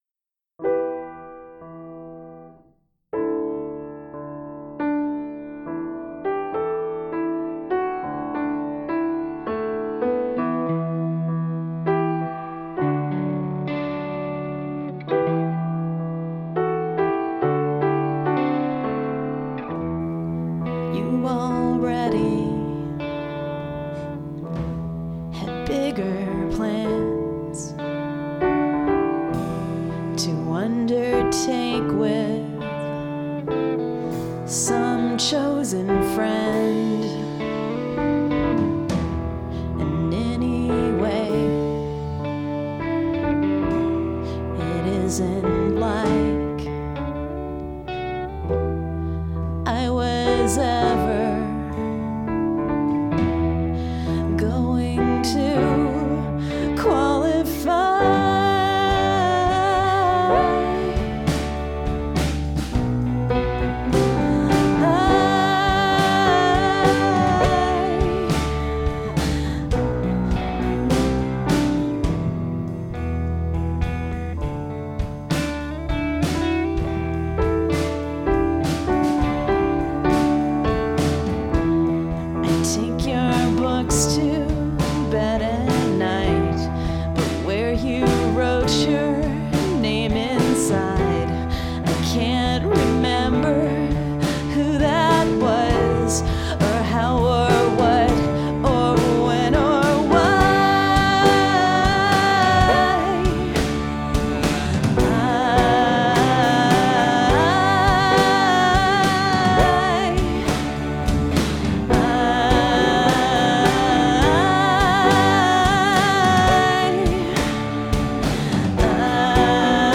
Gradually the structure was hammered from the verse and chorus figures.
The drums, piano, guitar and vocals were recorded simultaneously
Later I overdubbed the lead guitar at the end and the bass, the latter which is buried in the mix just to add a little low end.
A little reverb here and there, a little compression, a check of the rendered wav to make sure none of the samples had dropped out... oh gods, dropout is the enemy of using samples for songs.
It all fits together really well. I like the sproingy sounds.
I was hoping it'd come out sounding like pedal steel.
very cool, amazing that this was done in one day. powerful vocals.